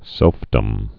(sĕlfdəm)